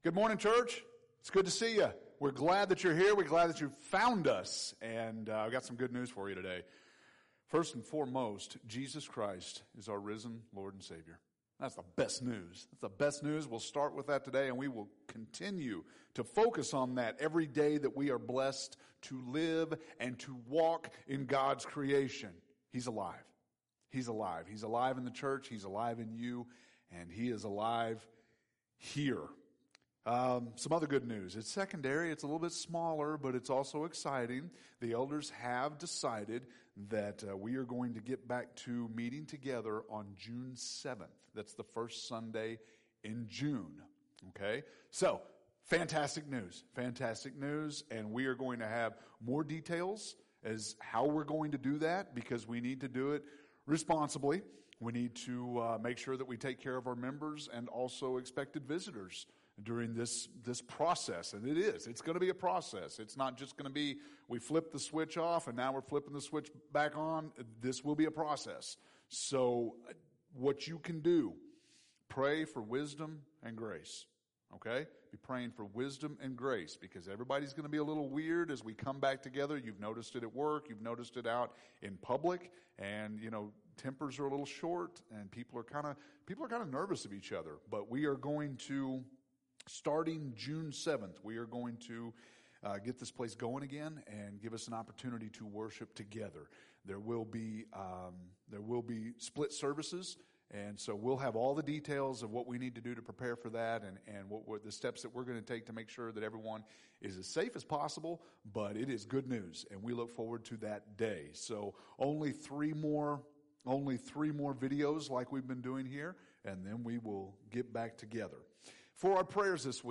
May 17th – Sermons